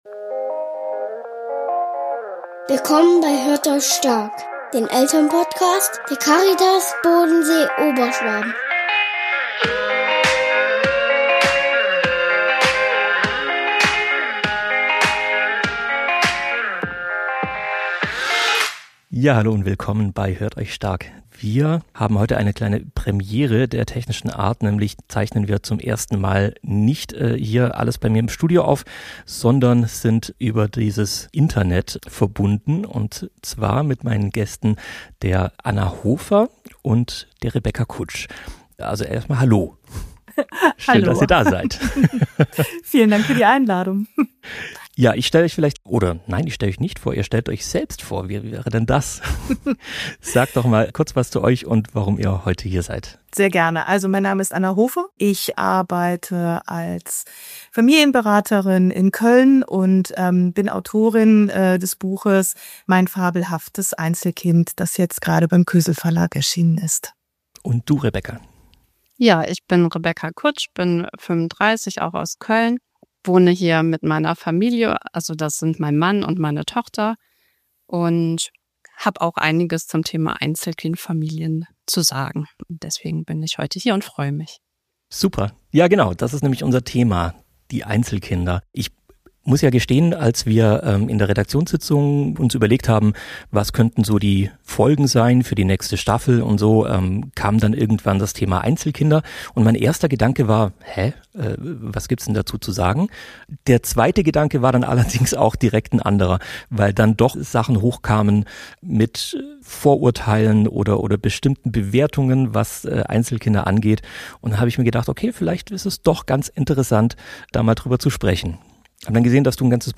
Oh du schaurige? Oh du stressige? Oh du auf jeden Fall anspruchsvolle Weihnachtszeit. Moderator